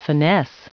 Prononciation du mot finesse en anglais (fichier audio)
Prononciation du mot : finesse